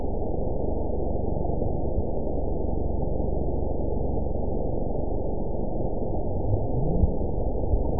event 917302 date 03/27/23 time 07:24:23 GMT (2 years, 1 month ago) score 9.18 location TSS-AB01 detected by nrw target species NRW annotations +NRW Spectrogram: Frequency (kHz) vs. Time (s) audio not available .wav